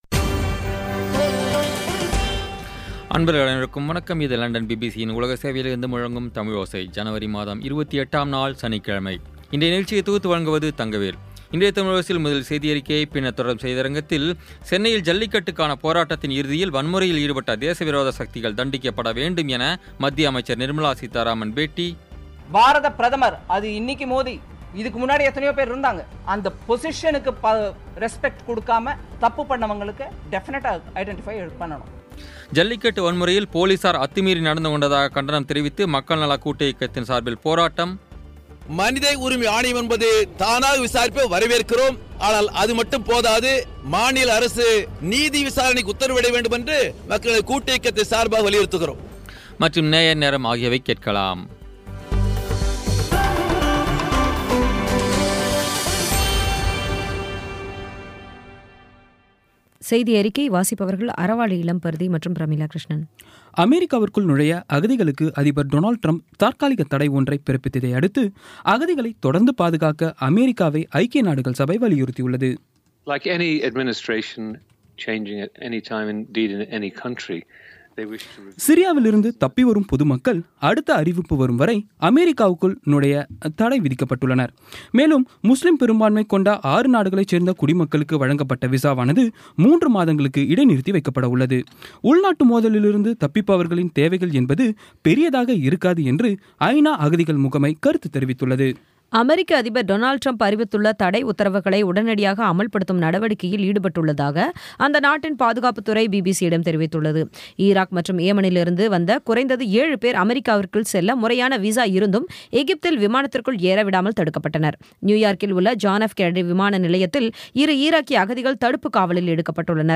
இன்றைய தமிழோசையில், சென்னையில் ஜல்லிக்கட்டுக்கான போராட்டத்தின் இறுதியில் வன்முறையில் ஈடுபட்ட தேசவிரோத சக்திகள் தண்டிக்கப்பட வேண்டும் என மத்திய அமைச்சர் நிர்மலா சீதாராமன் பேட்டி, ஜல்லிக்கட்டு வன்முறையில் போலீசார் அத்துமீறி நடந்துகொண்டதாகக் கண்டனம் தெரிவித்து மக்கள் நலக்கூட்டியக்கத்தின் சார்பில் போராட்டம் மற்றும் நேயர் நேரம் ஆகியவை கேட்கலாம்.